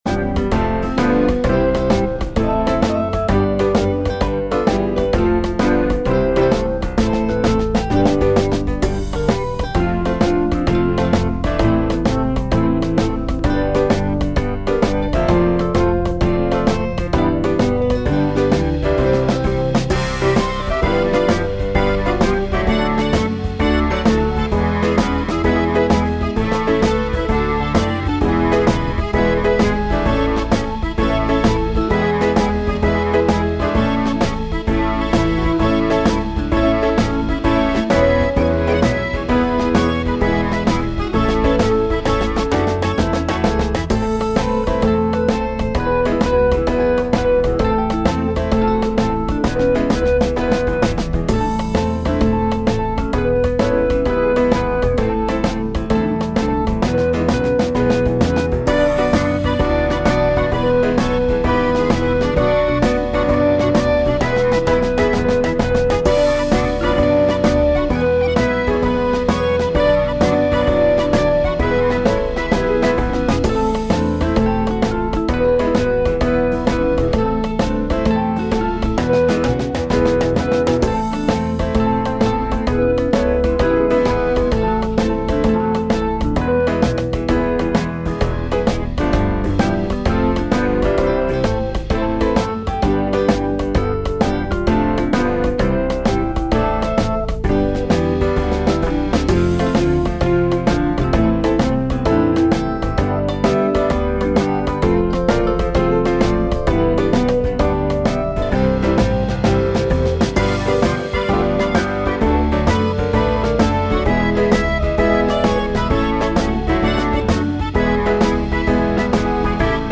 midi-demo 2 midi-demo 3